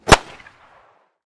fuze_rgd5.ogg